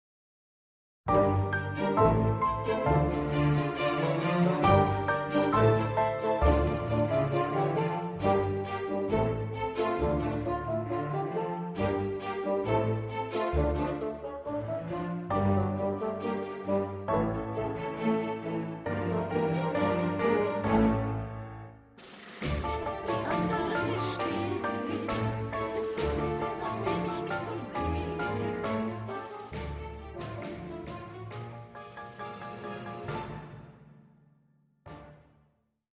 Aufführungsmaterial zum gleichnamigen Kindermusical
Schlagworte Aufführung • Kindermusical